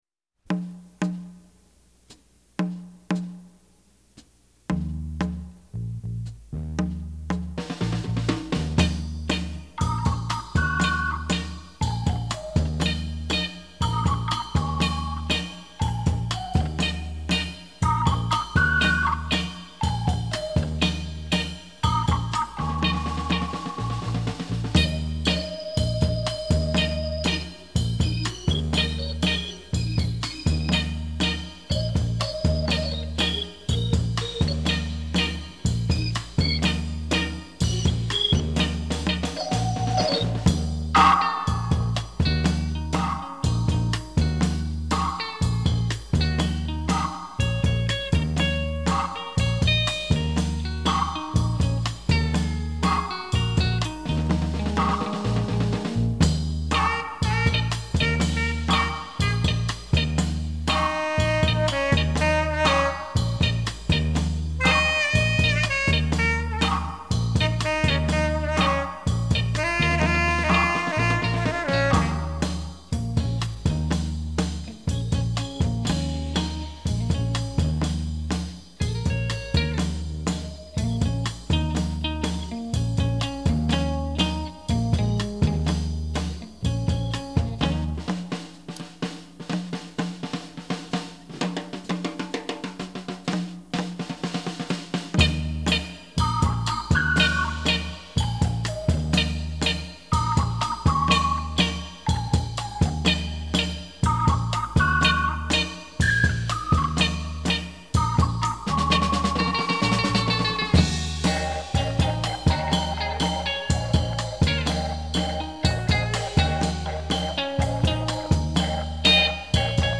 funky jamming